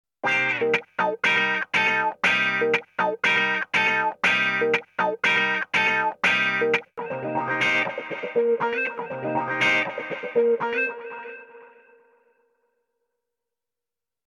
Wah technique avec Riff
ouais, enfin, je voyais plutôt des trucs du genre funk, je trouve un son sur internet pour bien cibler le truc...
Voilà, le fichier audio MP3 présente un des types de riff que je voudrai apprendre à jouer.